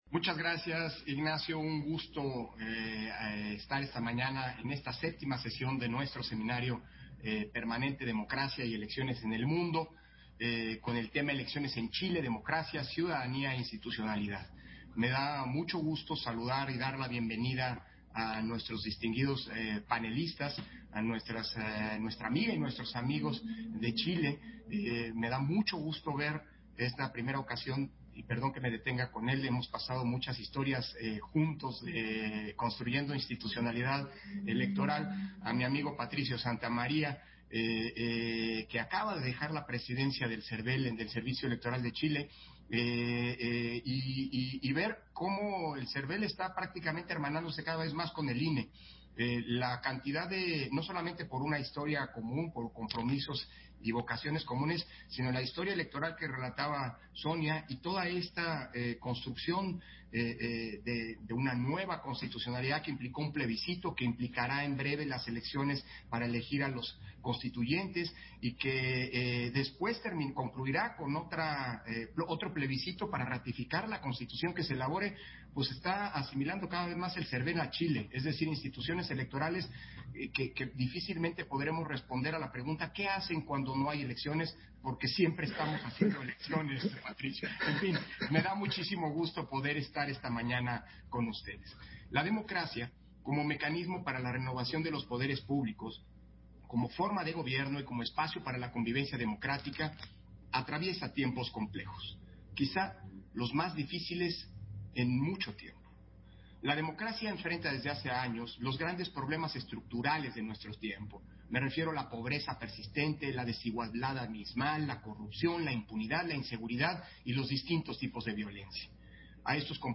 Intervención de Lorenzo Córdova, en la séptima sesión del seminario permanente Democracia y Elecciones en el Mundo